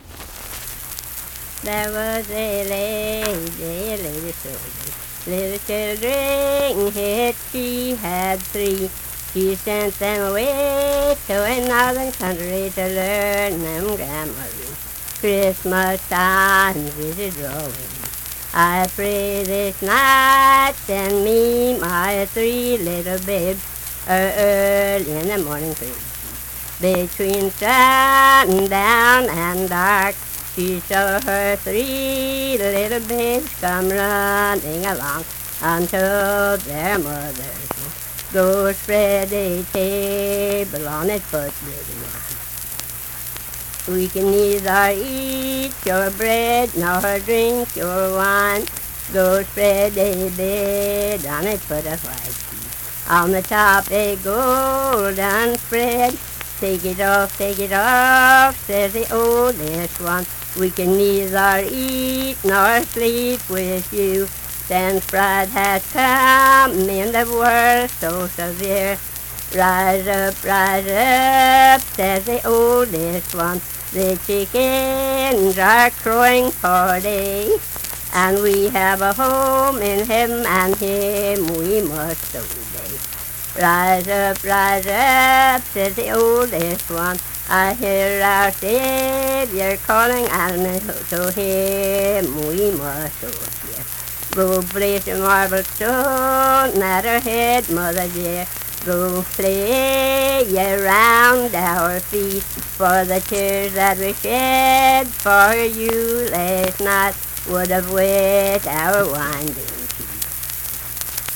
Unaccompanied vocal music
Verse-refrain 9(2-4).
Voice (sung)
Lundale (W. Va.), Logan County (W. Va.)